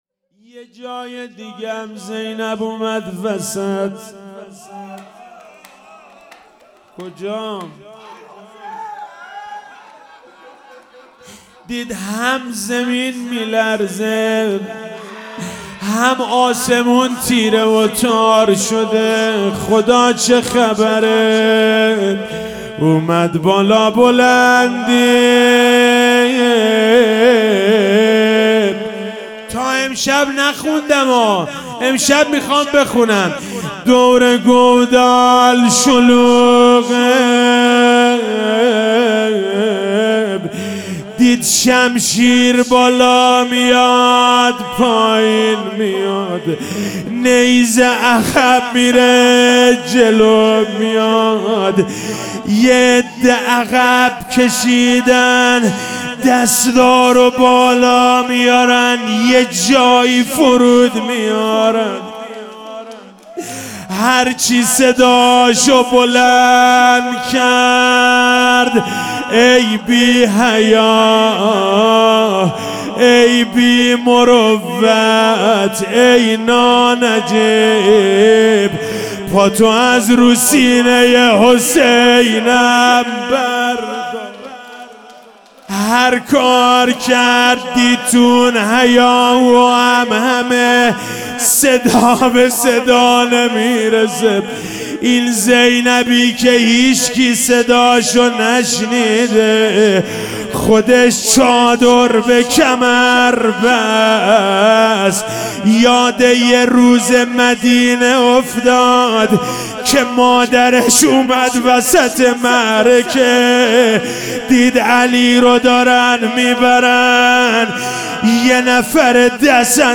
شب پنجم محرم 1399